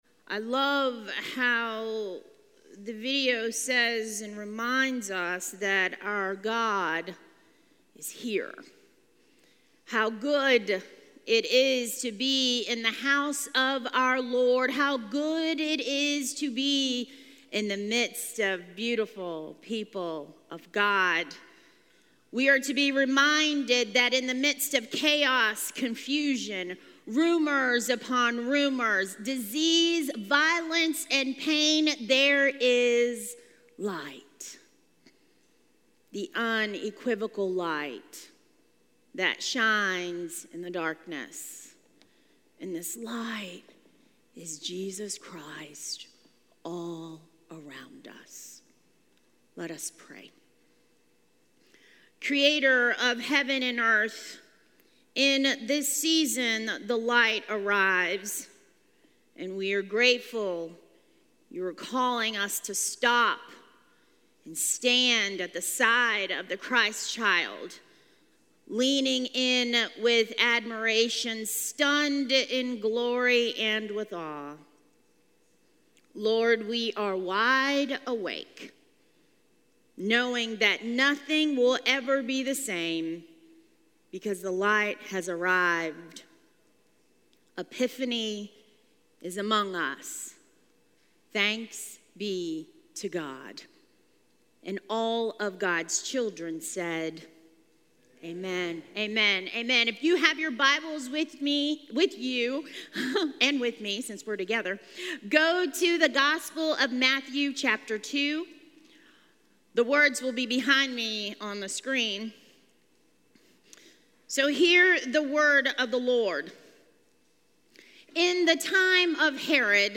A message from the series "Unexpected Gift."